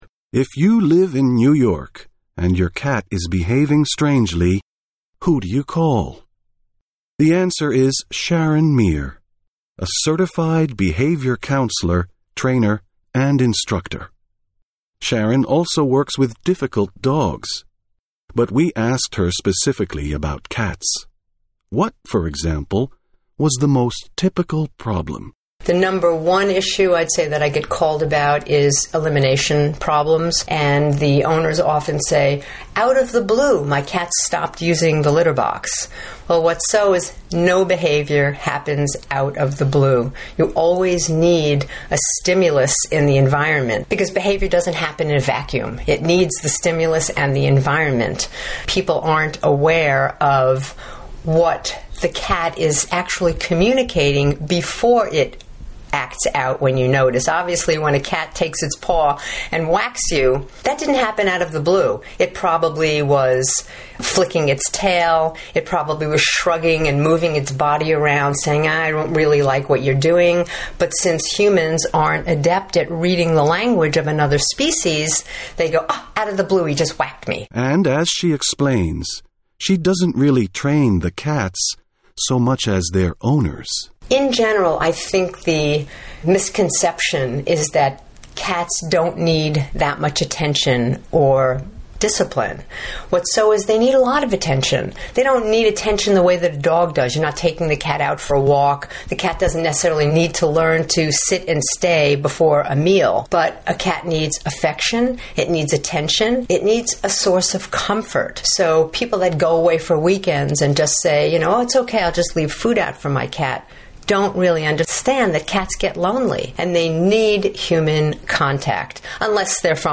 INTERVIEW: out of the blue